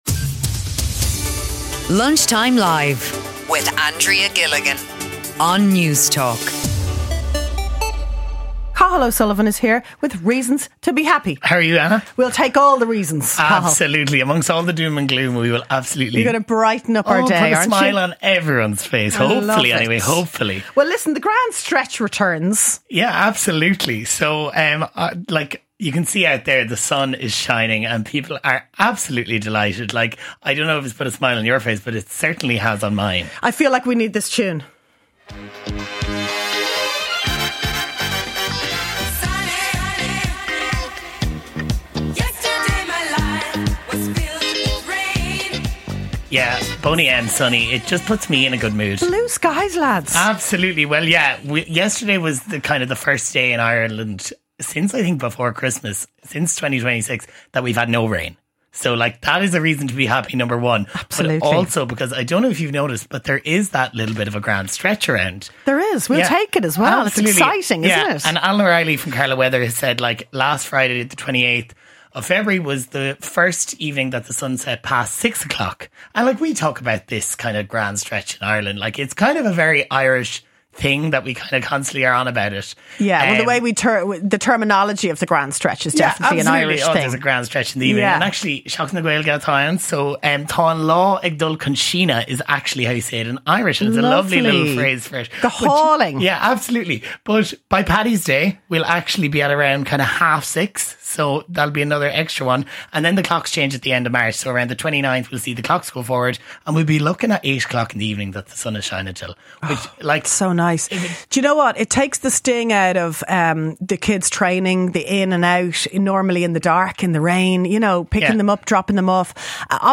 in the studio to put a smile on your faces…